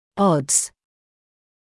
[ɔdz][одз]вероятность, шанс; трудности, сложности